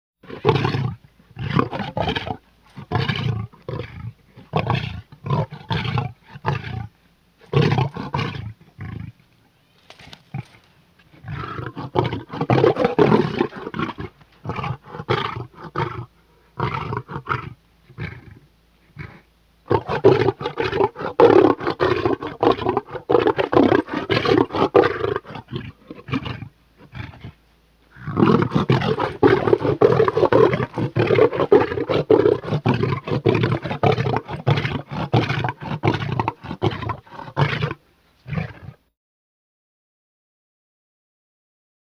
Tiger Grunt Growling Sound
animal
Tiger Grunt Growling